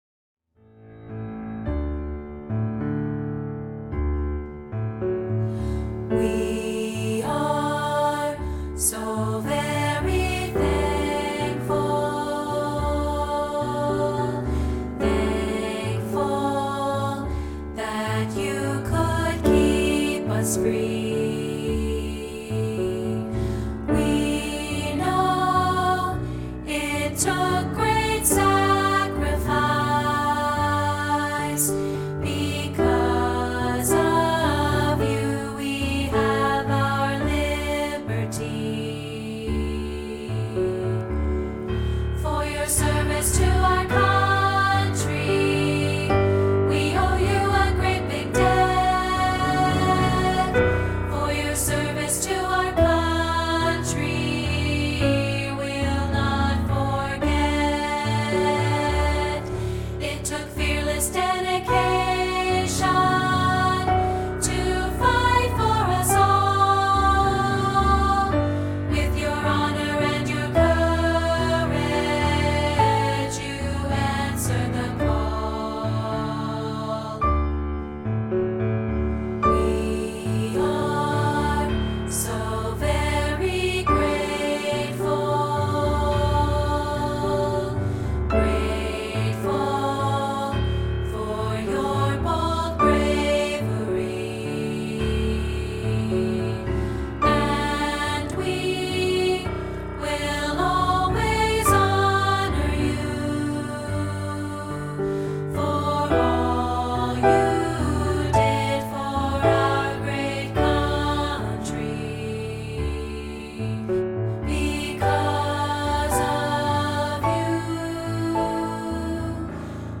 This is a rehearsal track of part 2, isolated.